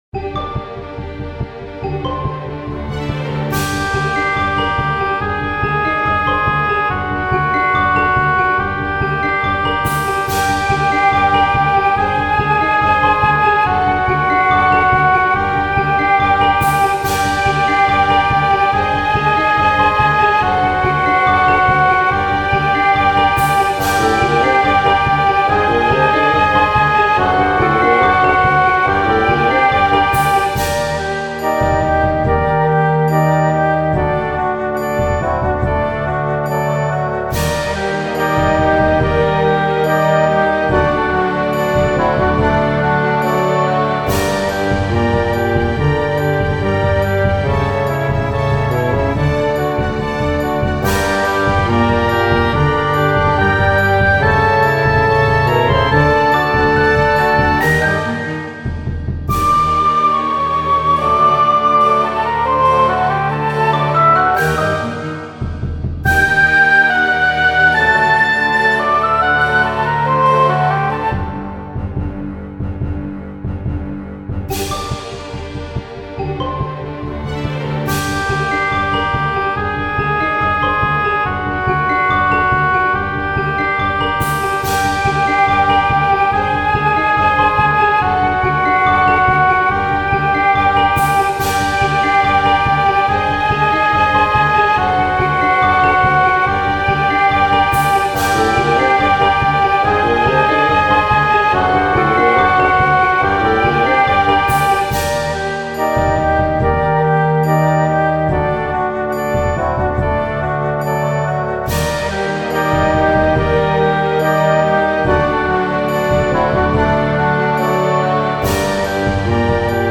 イメージ：ダンジョン 緊迫   カテゴリ：RPG−外・ダンジョン